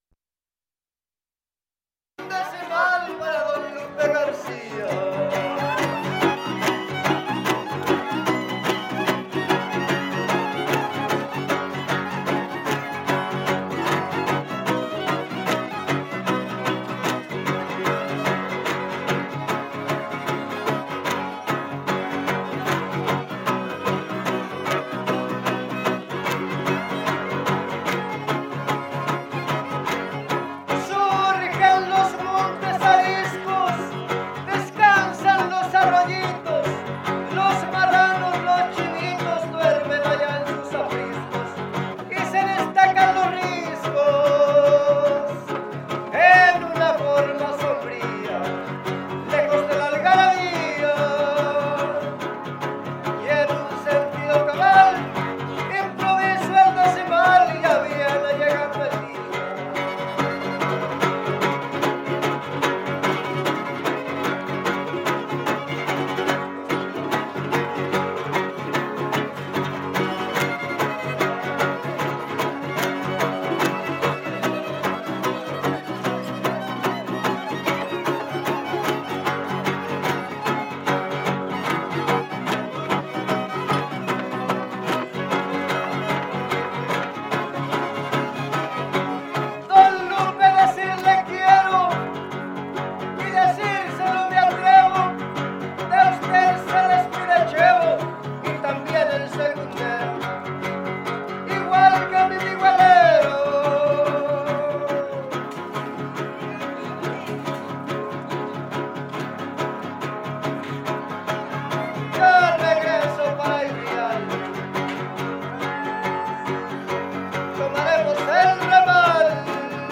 Topada ejidal: Cárdenas, San Luis Potosí